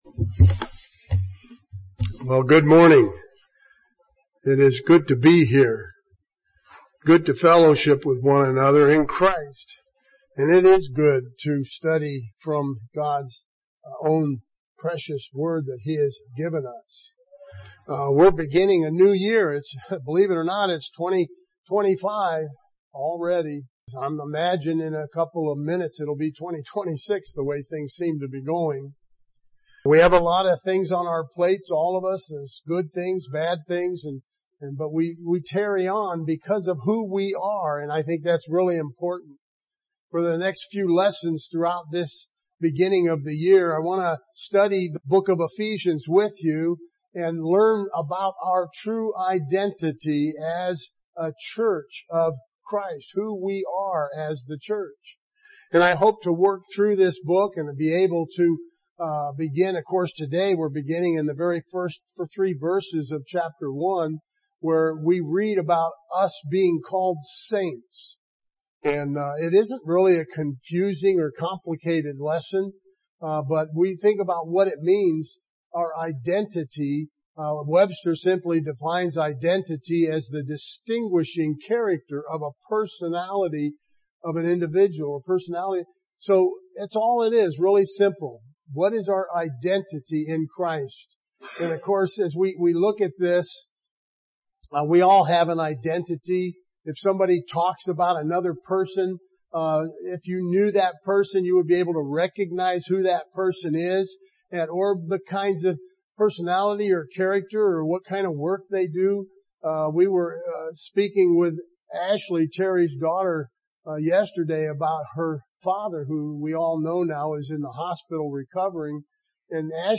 For the next few weeks our Sunday morning lessons will come from the letter Paul wrote to the saints in Ephesus. My hope is to help us, the church, to be more firmly established in the knowledge of who we are and why we are here.